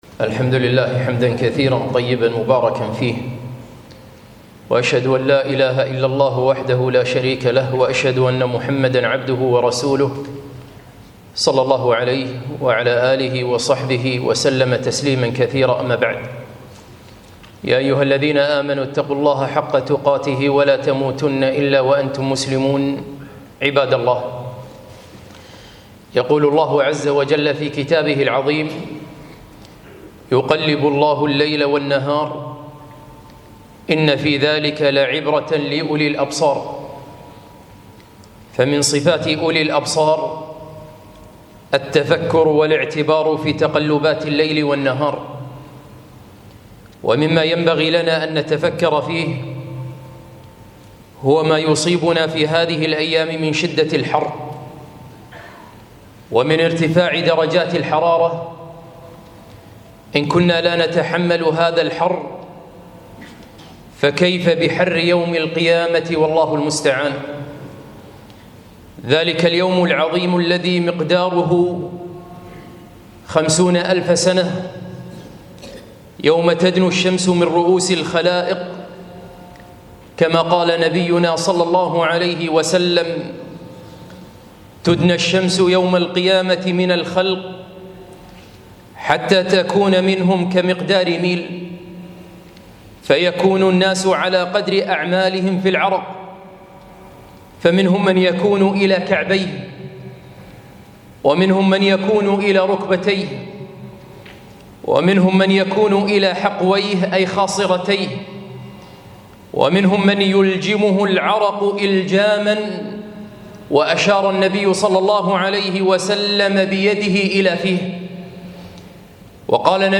خطبة - ولنا في فصل الصيف عبرة